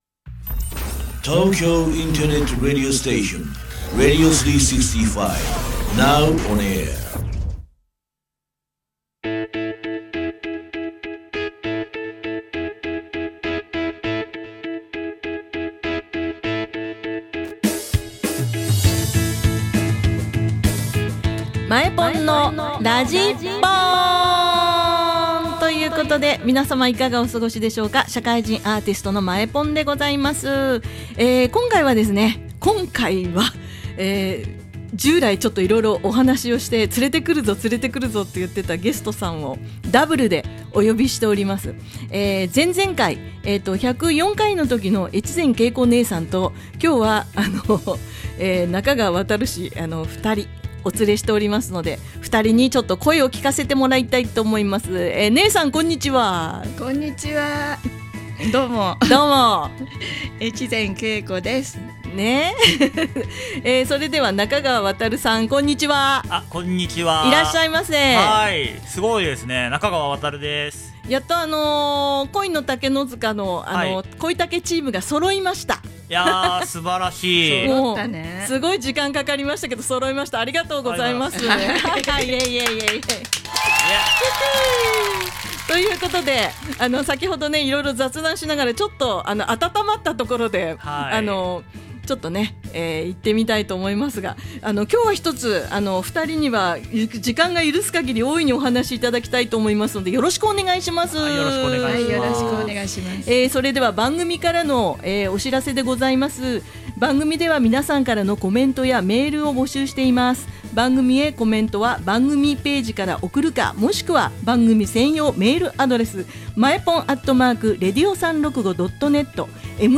この番組は大人がクスッと笑えるラジオを目指しています。